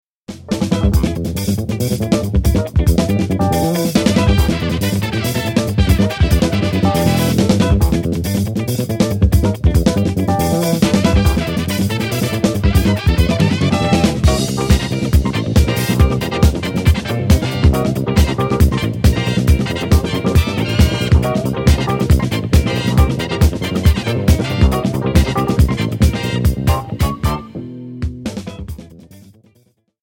Méthode pour Guitare basse